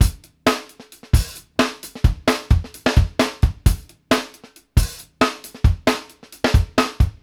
FUNK+OPN H-L.wav